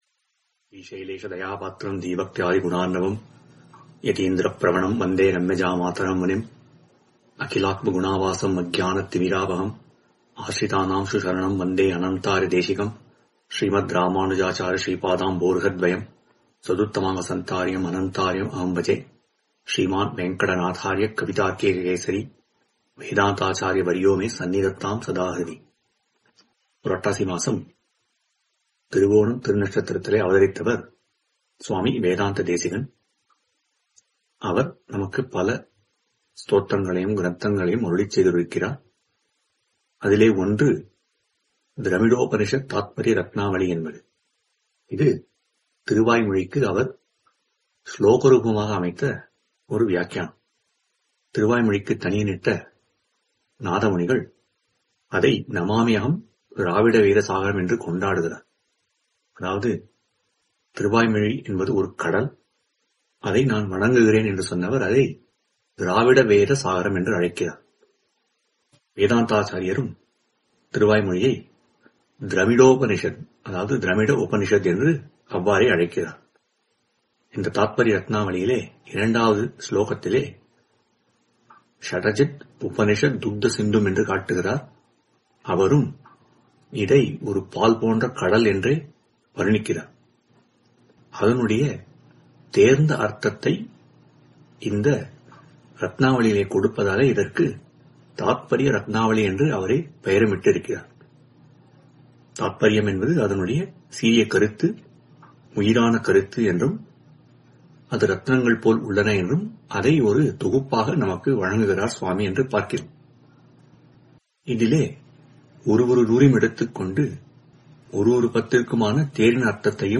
Dramidopanishat Thathparya Rathnavali – 6th Centum – 2023-Purattasi Upanyasangal